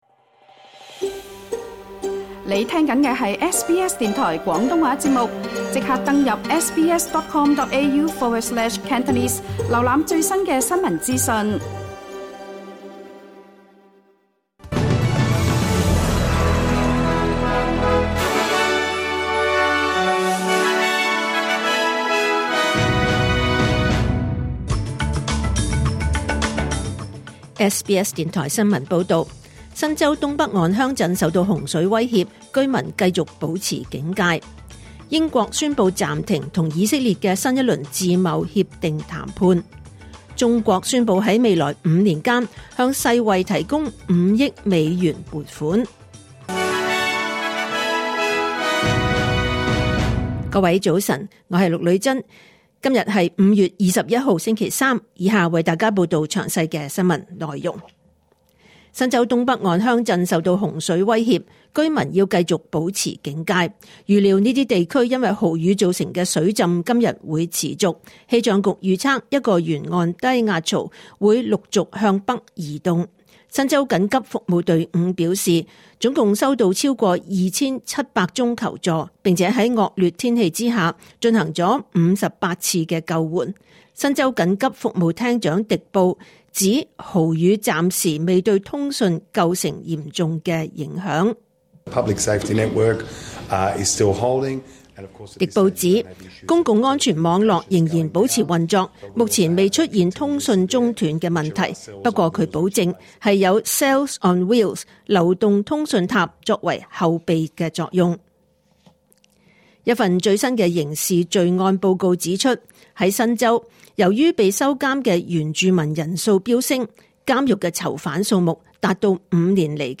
2025年5月21日 SBS 廣東話節目九點半新聞報道。